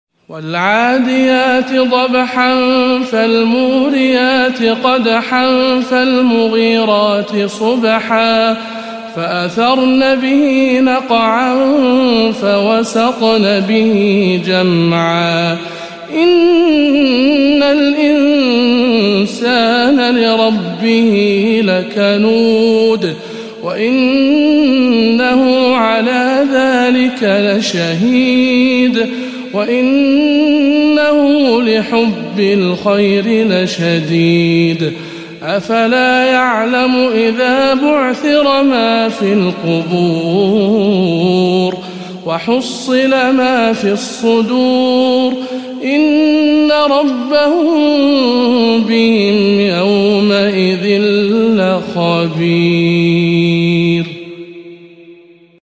سورة العاديات - تلاوات رمضان 1437 هـ